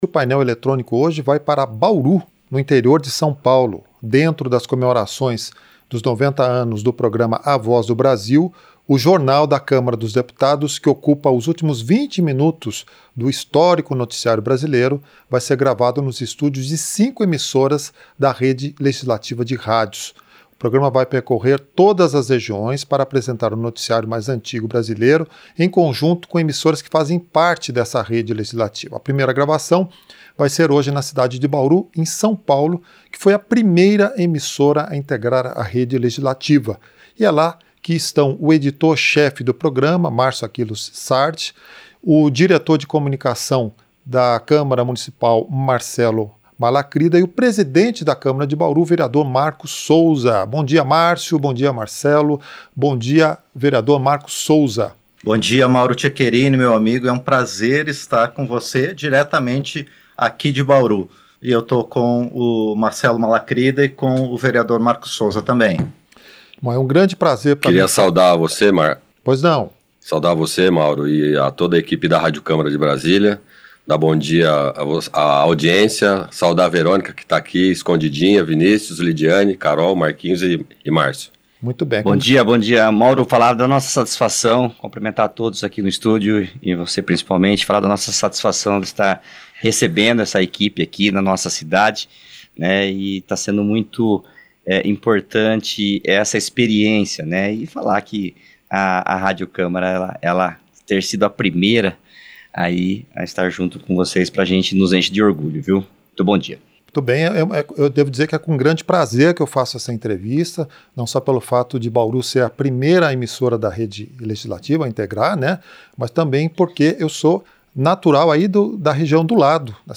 A primeira gravação, que vai ao ar nesta sexta-feira, foi feita na cidade de Bauru, no interior Oeste de São Paulo, primeira emissora a integrar a Rede Legislativa.
Entrevista